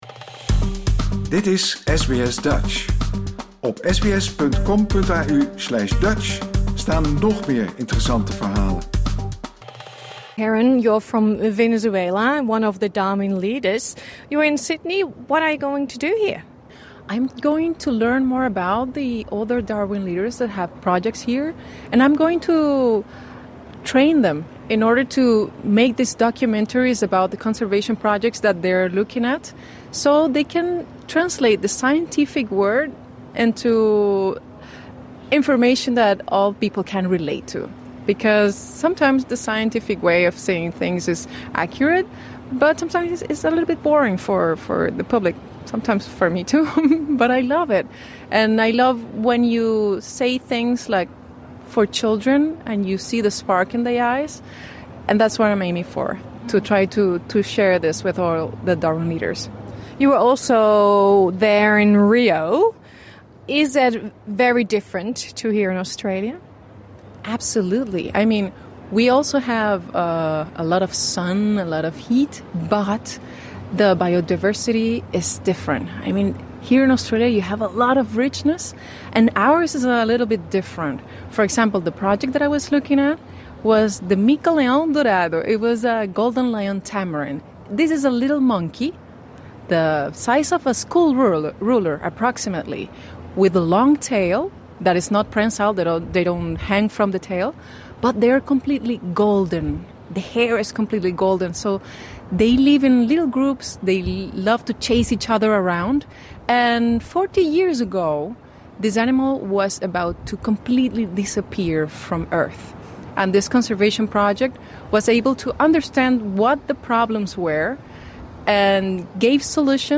Dit interview is in het Engels.